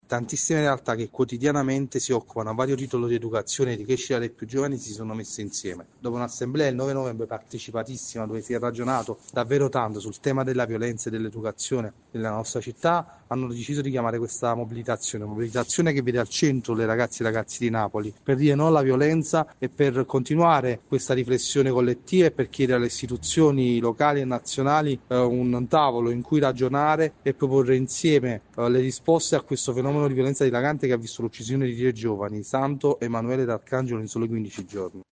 Attacco inaccettabile – Condanna unanime dell’aggressione alla sede de La Stampa avvenuta venerdì a Torino. Ascoltiamo il portavoce di Articolo Ventuno Giuseppe Giulietti.